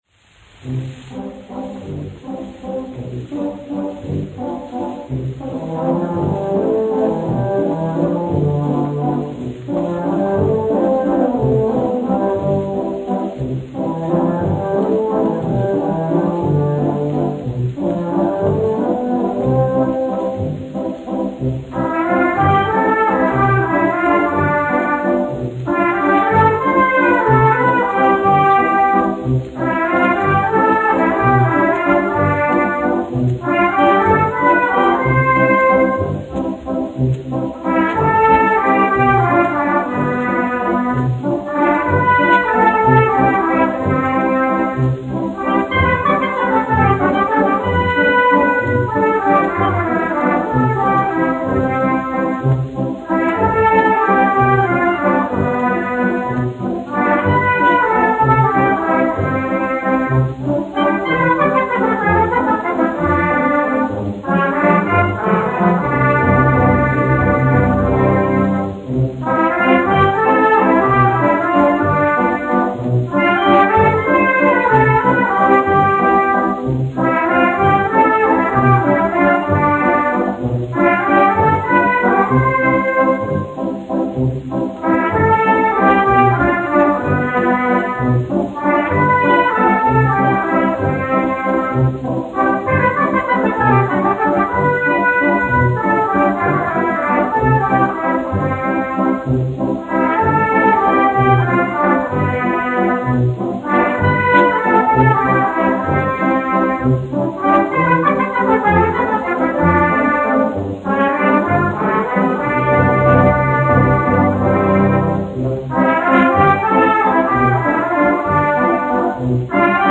1 skpl. : analogs, 78 apgr/min, mono ; 25 cm
Pūtēju orķestra mūzika, aranžējumi
Latvijas vēsturiskie šellaka skaņuplašu ieraksti (Kolekcija)